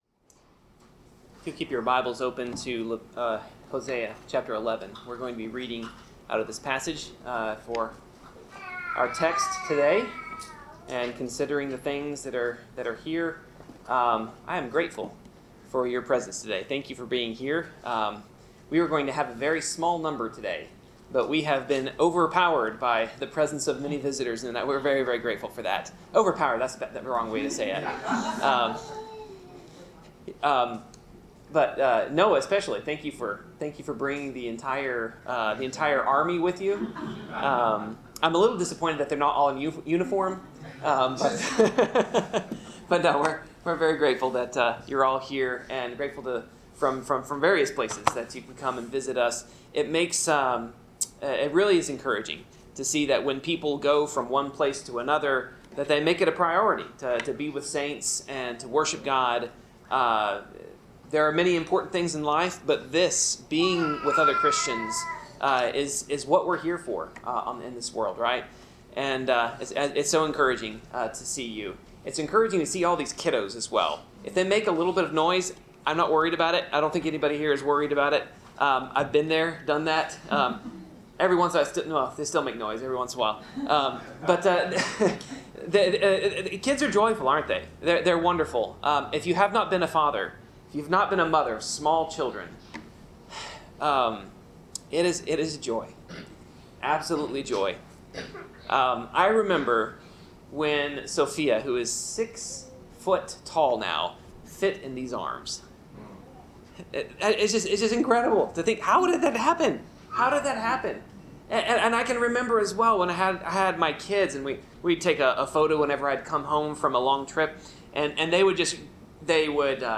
Passage: Hosea 11 Service Type: Sermon